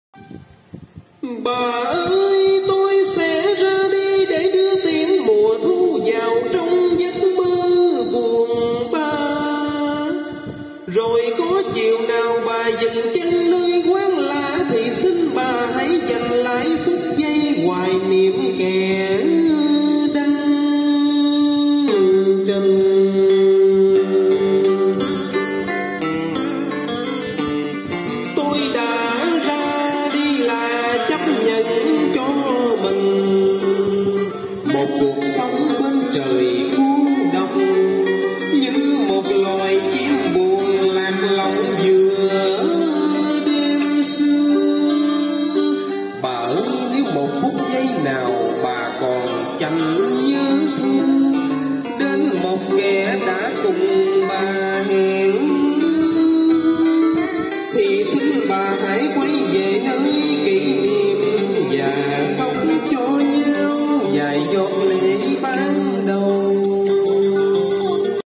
Test mixer Beheringer reverb quá